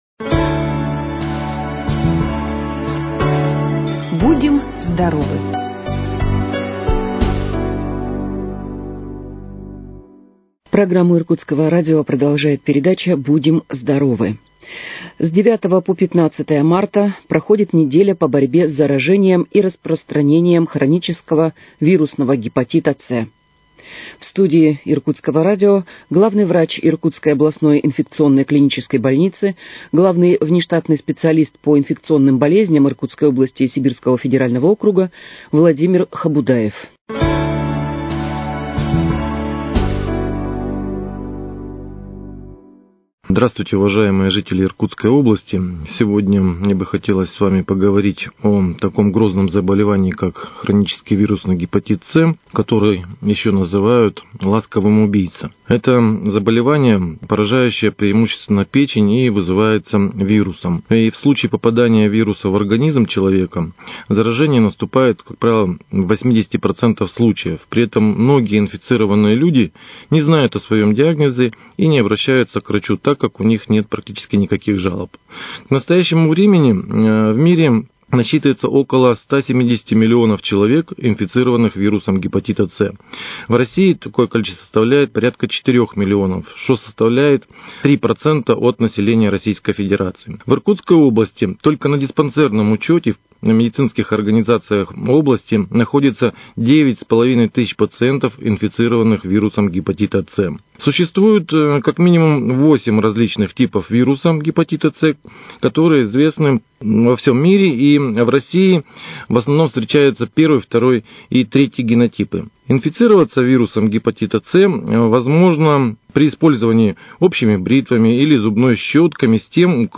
В студии Иркутского радио